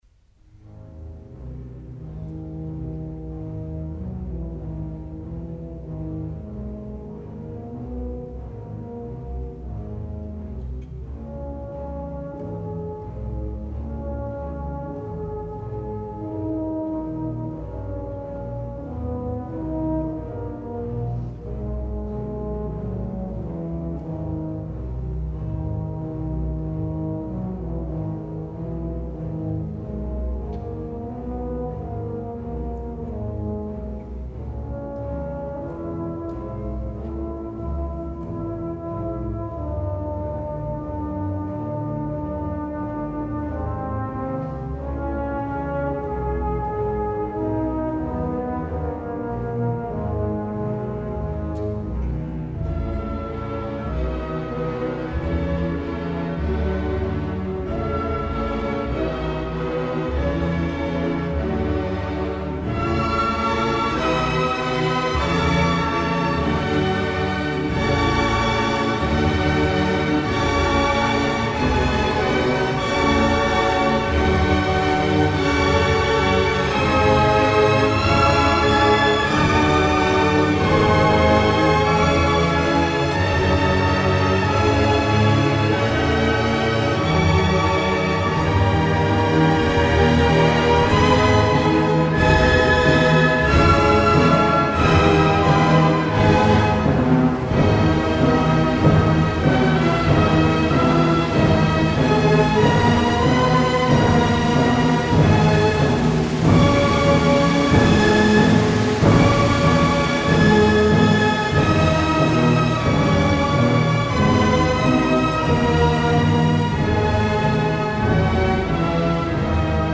1. Tema principale (note gravi)
Bydlo (orchestrazione di Ravel)